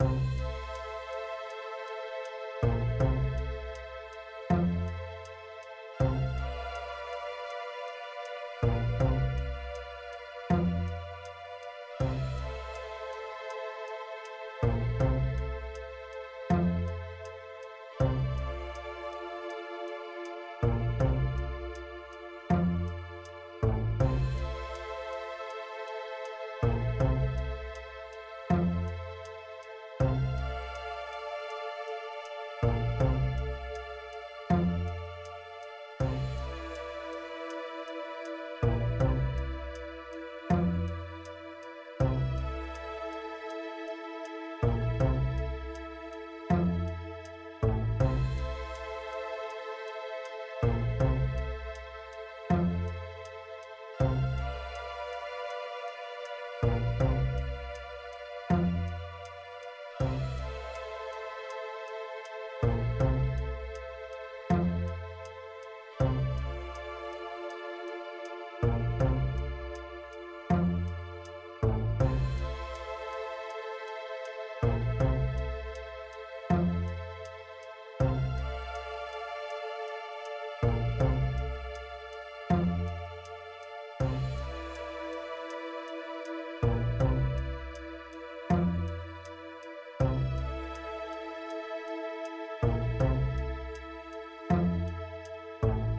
Audio material from the game
Ambientsound map courthouse